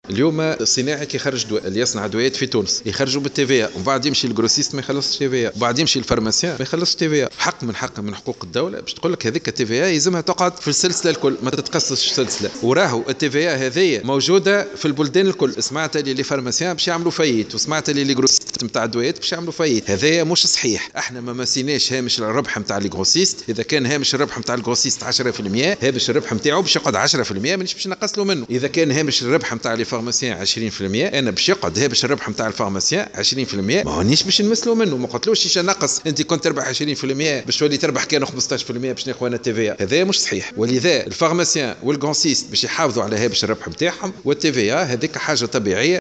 وأوضح أن هذه الضريبة هي شاملة في الأصل للجميع ويدفعها جميع المتدخلين في السلسلة الإنتاجية، وجاءت هذه التصريحات على هامش الاجتماع الدوري للجنة المشتركة للحوكمة المفتوحة والشفافية المالية مع منظمات المجتمع المدني.